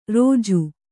♪ rōju